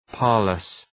Προφορά
{‘pɑ:rləs}